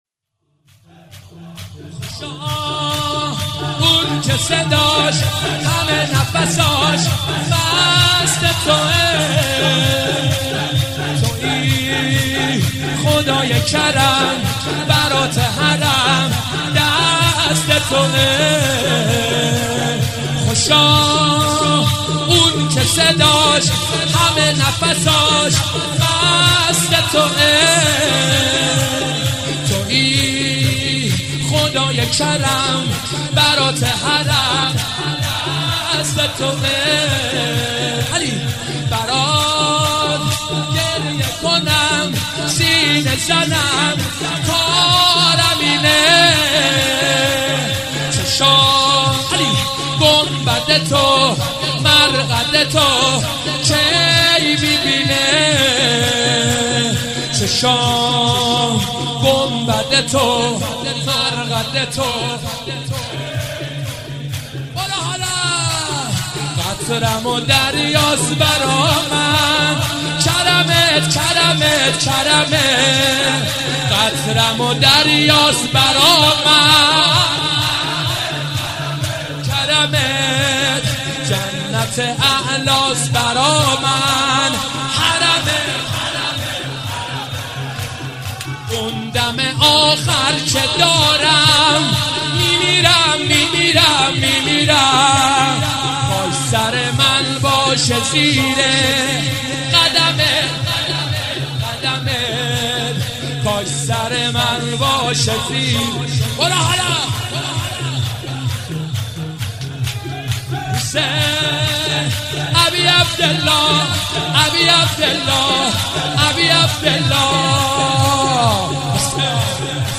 مراسم شب شهادت امام جواد علیه السلام
خوشا اون که(شور)